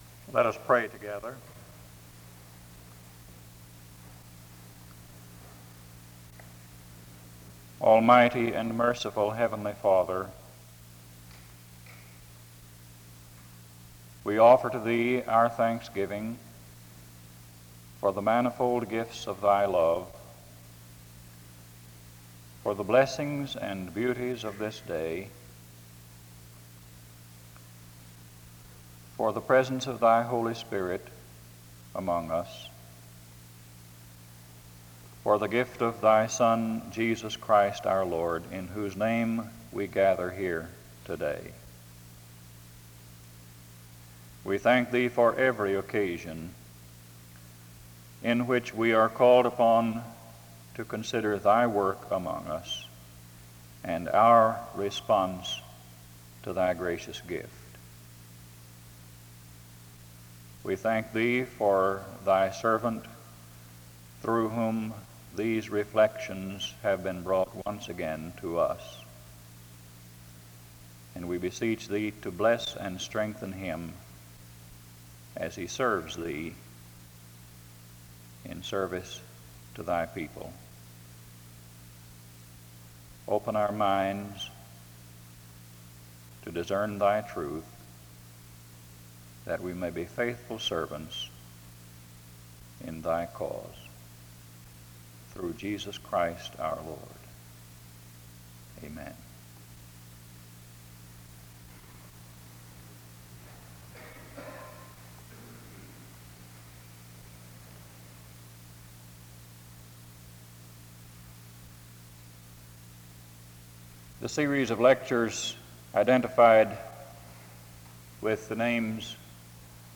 The service opens in a word of prayer from 0:00-1:39.
A closing prayer is offered from 49:08-49:39. This is part 4 of a 4 part lecture series.
SEBTS Chapel and Special Event Recordings